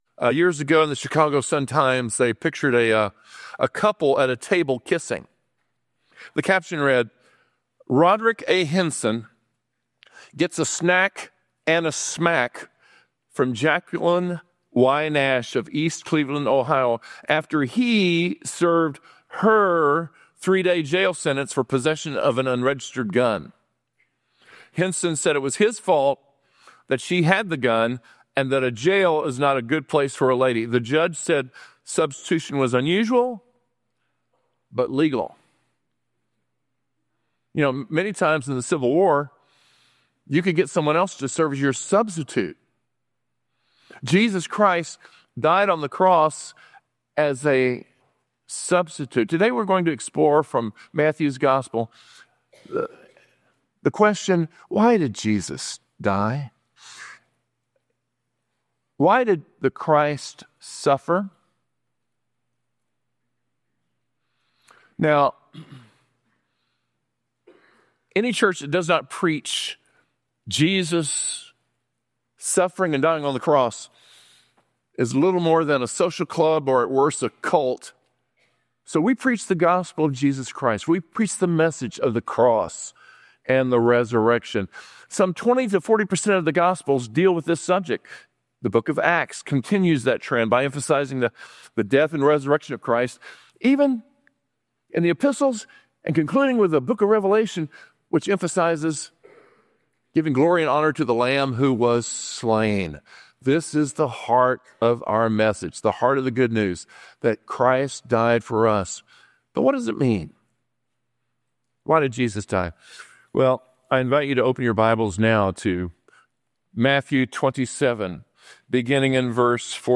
A message from the series "Worship Matters."